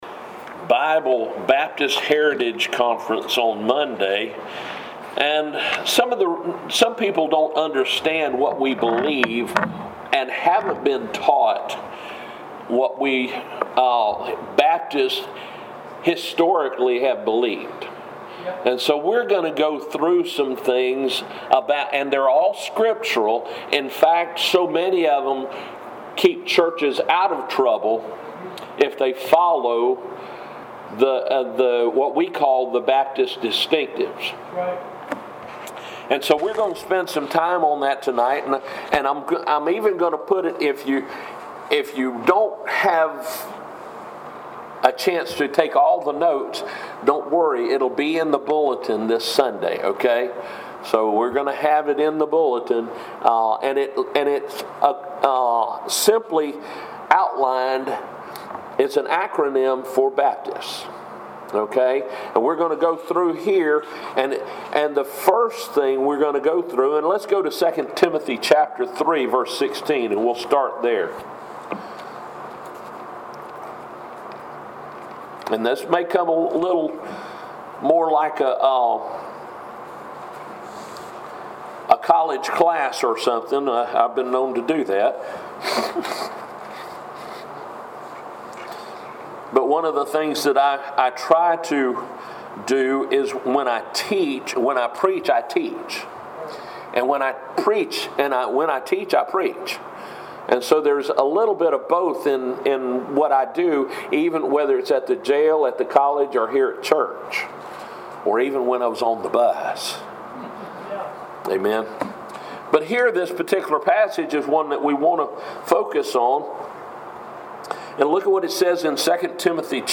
Sermons
7/14/2021 Wednesday Service